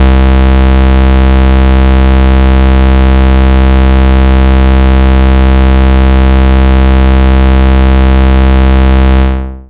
Big Phat Square.wav